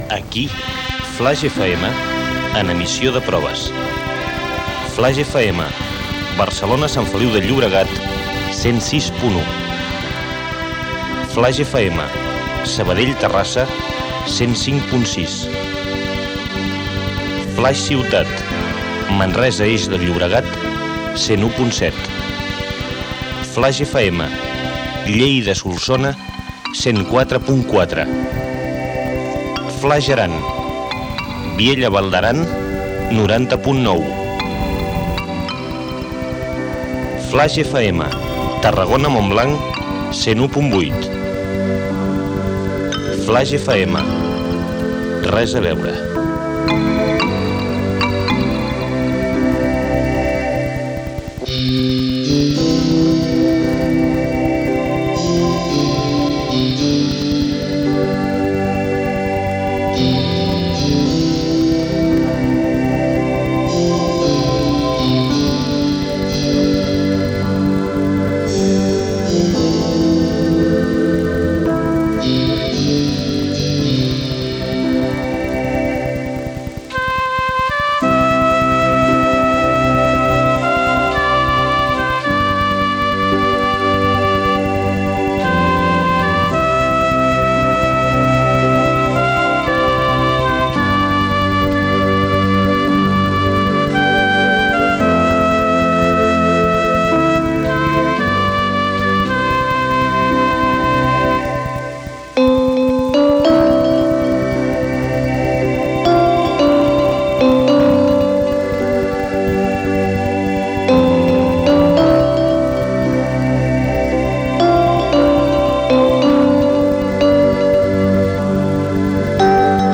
Identificació de l'emissió en proves, freqüències i localitats d'emissió.
Emissió en proves.